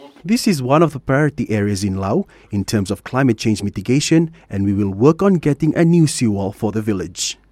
Agriculture and Waterways Minister, Vatimi Rayalu.